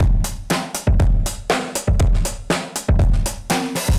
Index of /musicradar/dusty-funk-samples/Beats/120bpm/Alt Sound
DF_BeatB[dustier]_120-03.wav